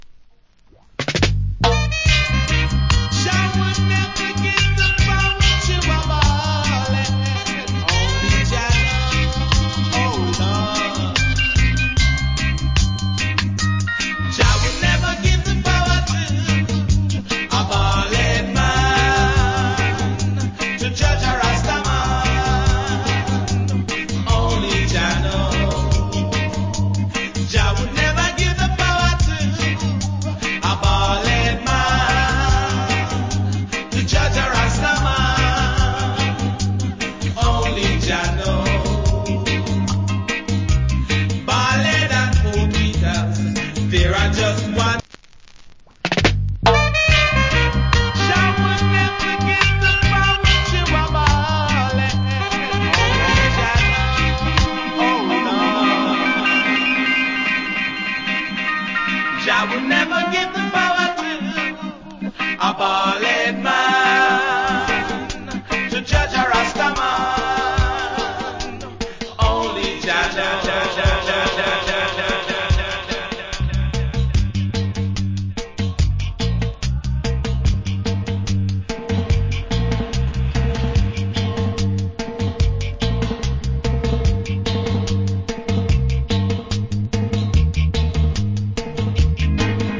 Foundation Roots.